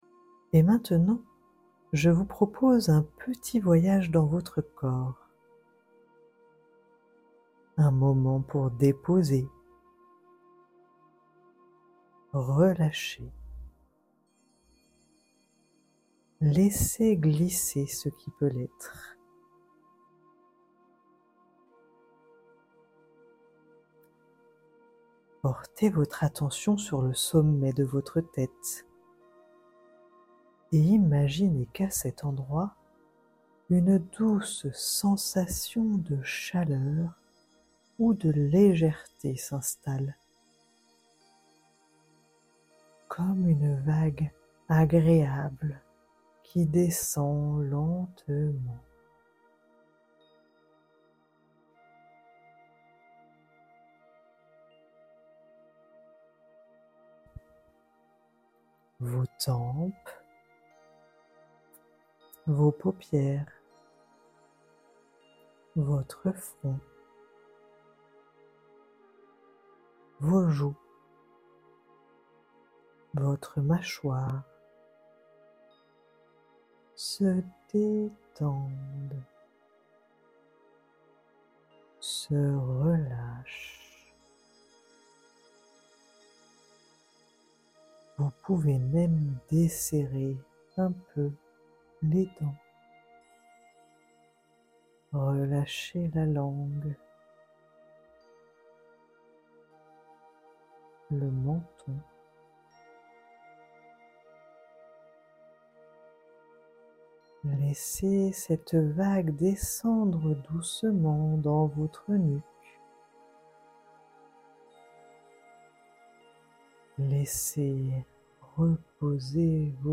🌟 10 méditations guidées de 12 à 15min
Oui ! Ces audios sont guidés pas à pas, avec une voix douce. Aucun prérequis n’est nécessaire.